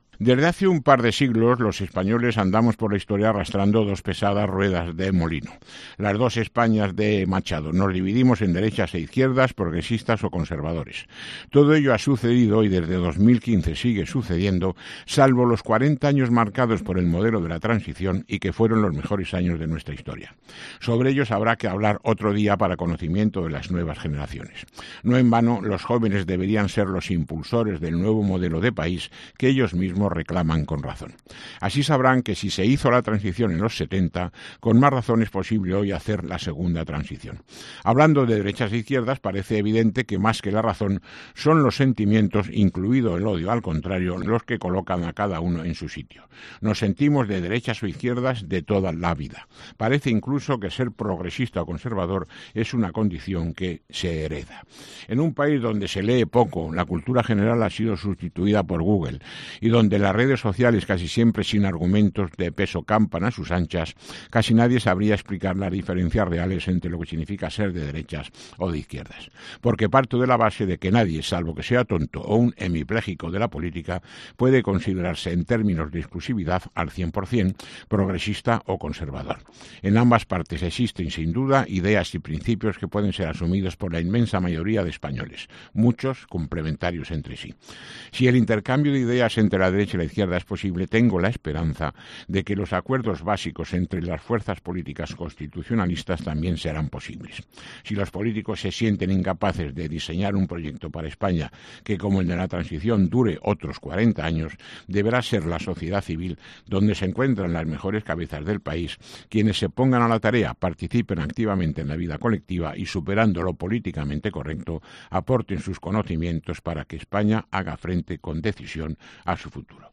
Jose Ángel Biel, ex vicepresidente del Gobierno de Aragón y ex presidente del PAR, se acerca cada lunes a nuestros micrófonos para exponer su particular visión de la actualidad regional y nacional.